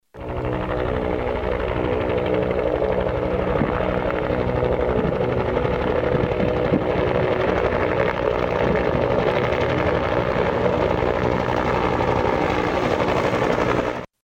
Huey Helicopter Sound & Vibration
UH1D_Huey.mp3